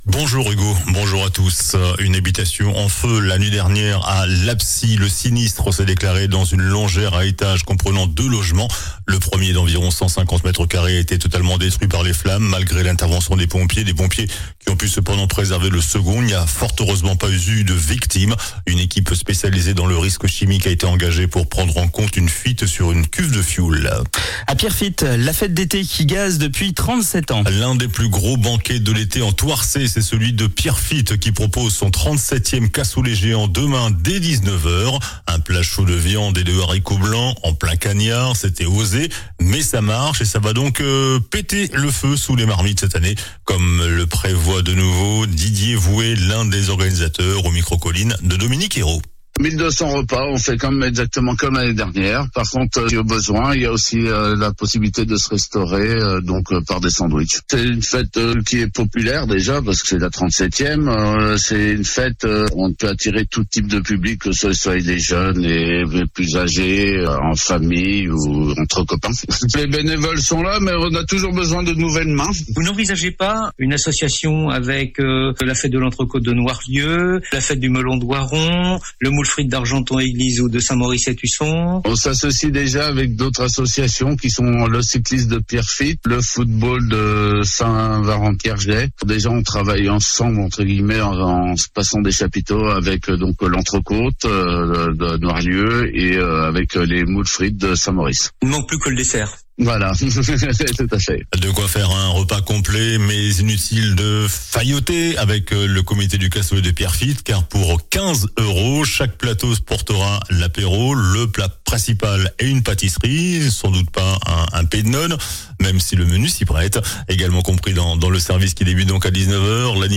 JOURNAL DU SAMEDI 02 AOUT